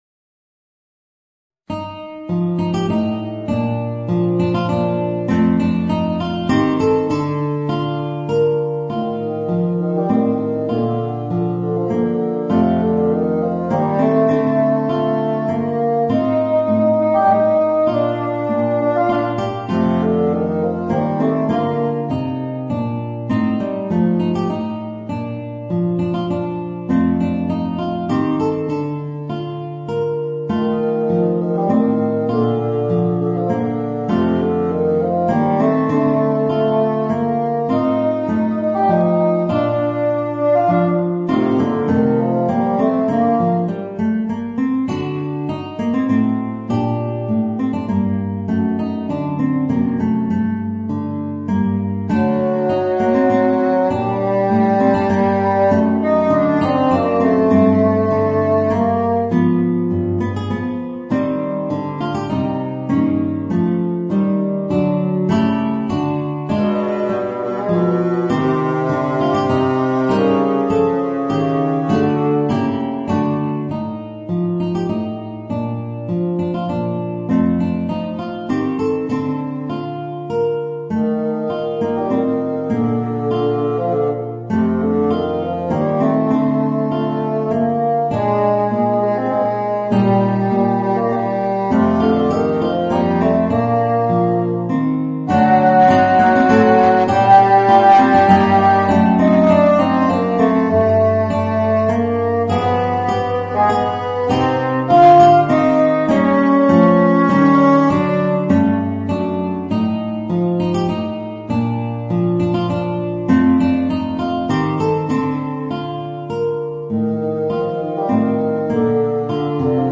Voicing: Bassoon and Guitar